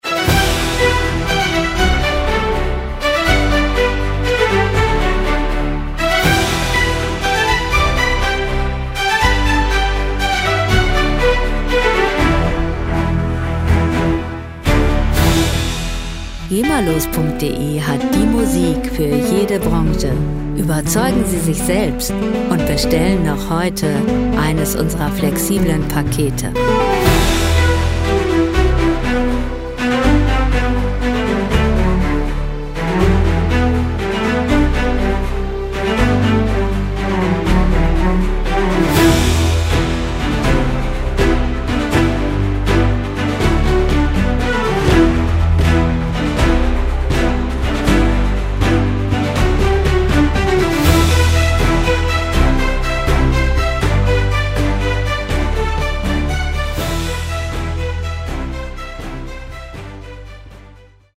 lizenzfreie Werbemusik für Imagefilme
Musikstil: Werbemusik
Tempo: 121 bpm
Tonart: E-Moll
Charakter: stark, kräftig
Instrumentierung: Orchester, Percussion, Synthesizer